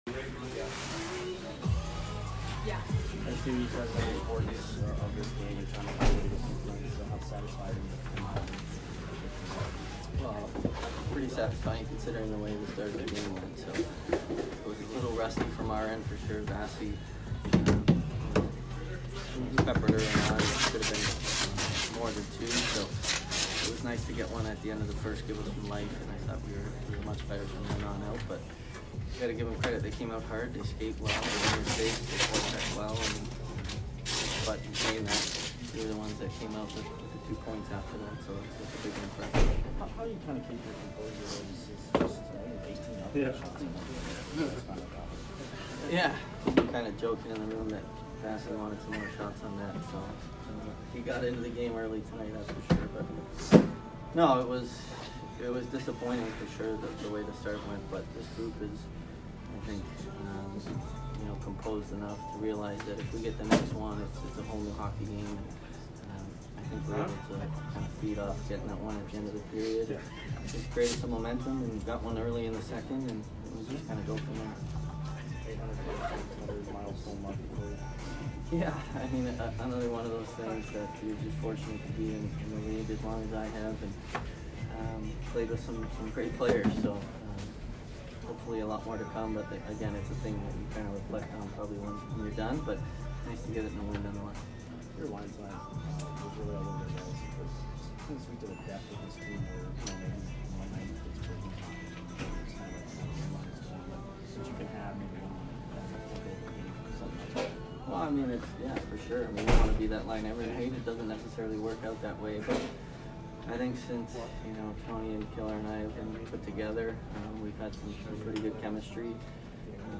Stamkos post-game 12/28